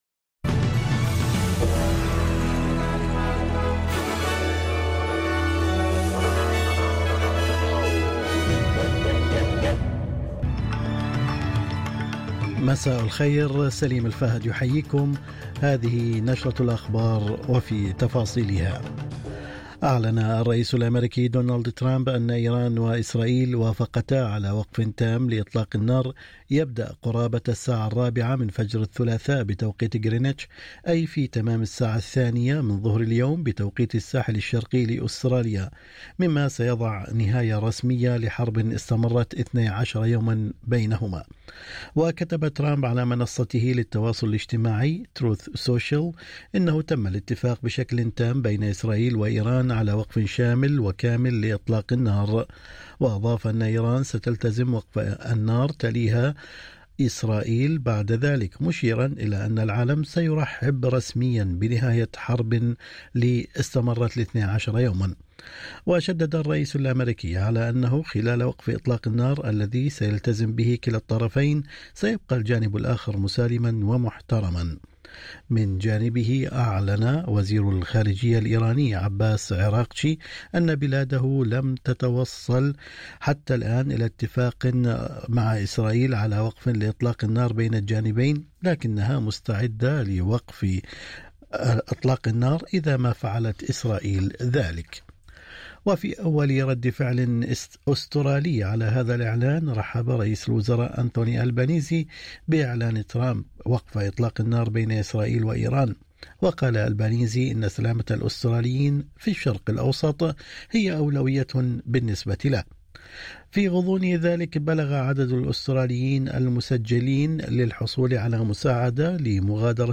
نشرة أخبار الظهيرة 24/06/2025